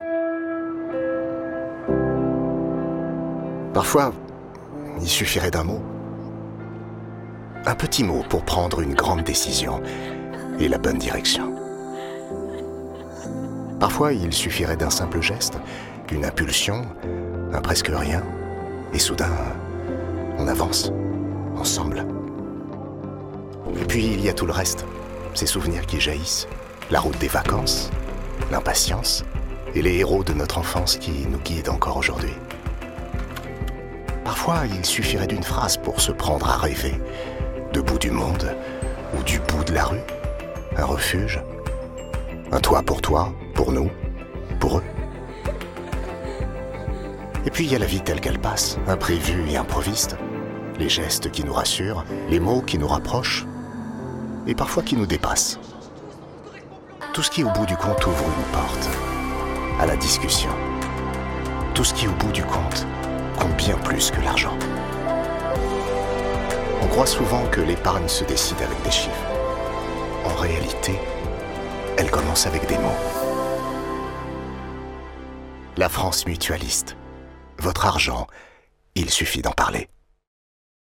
Parlé et intérieur.